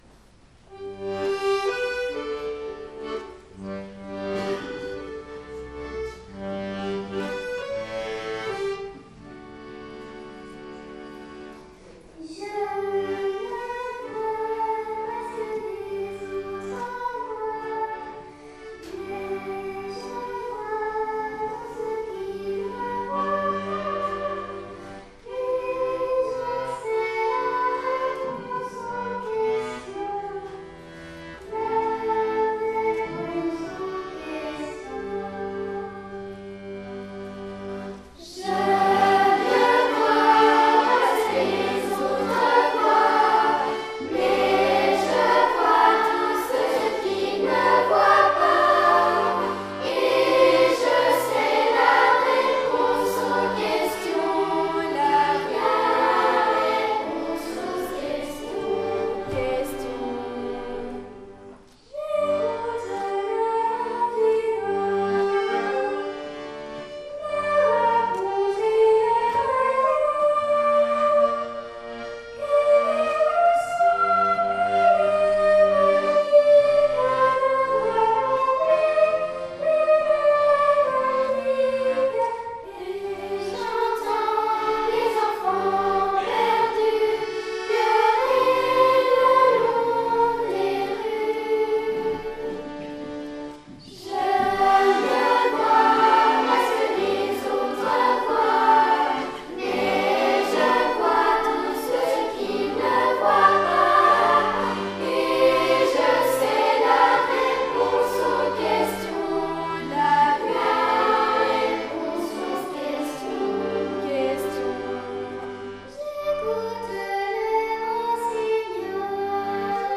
Concert (Fribourg) - Choeur d'enfants La Voix du Gibloux
CHOEUR DES GRANDS